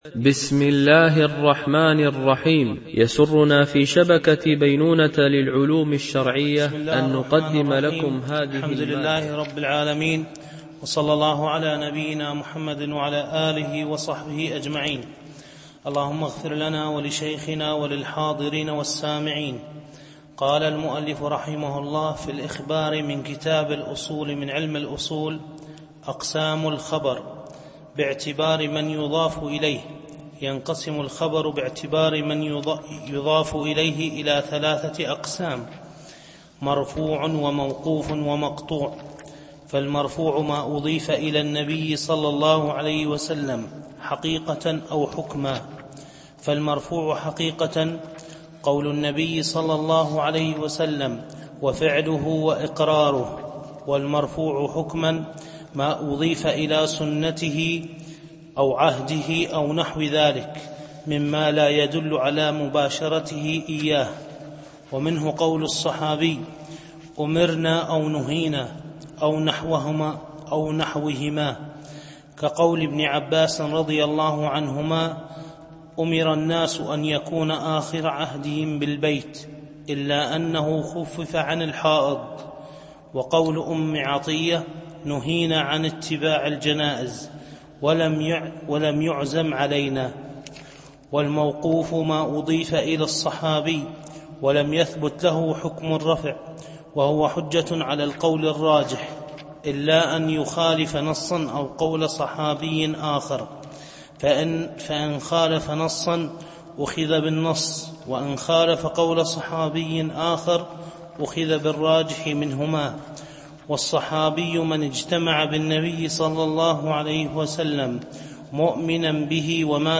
شرح الأصول من علم الأصول ـ الدرس 21 (الأخبار الجزء الثاني)